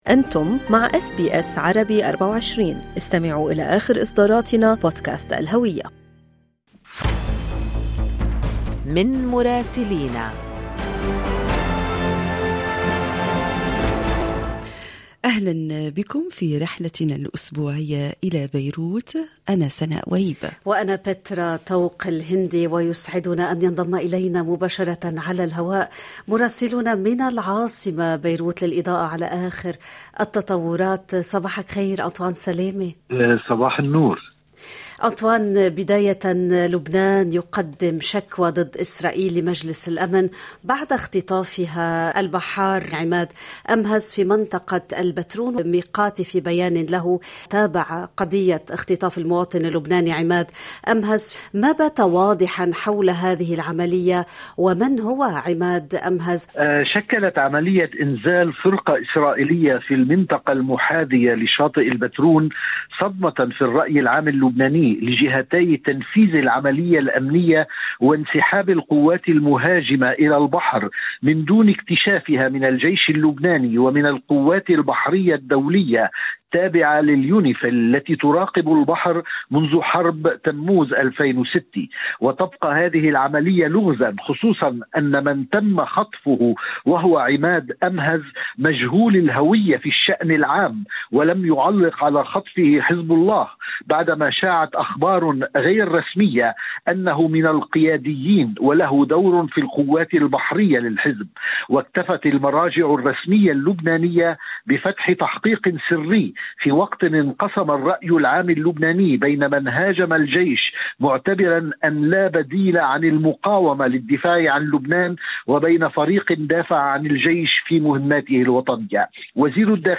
يمكنكم الاستماع إلى تقرير مراسلنا في العاصمة بيروت بالضغط على التسجيل الصوتي أعلاه.